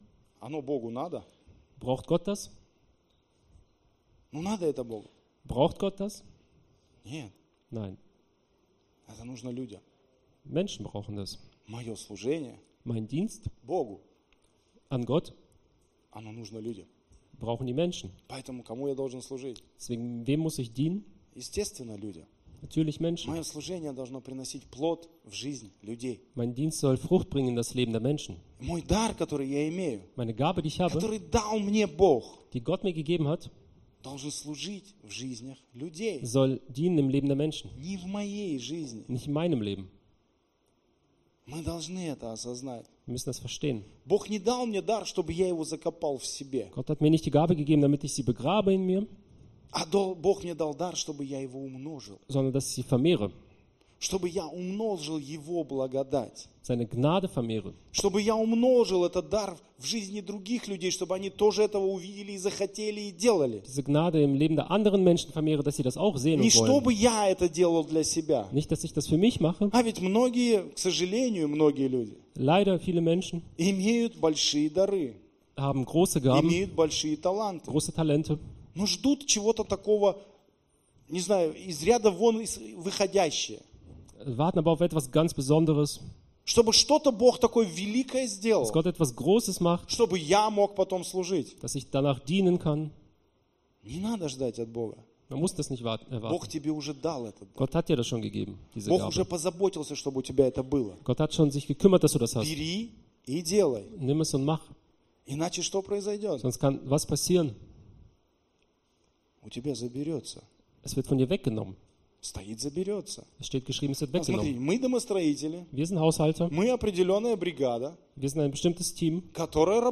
Predigt Archive - WERA Castrop
Gottesdienst